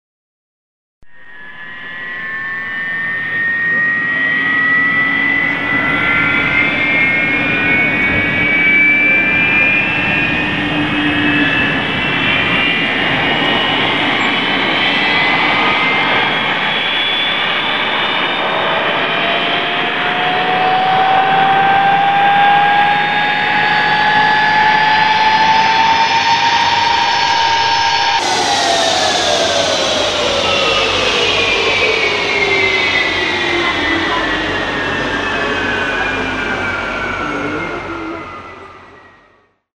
Звук моторчика метлы Бабы-Яги при взлете и посадке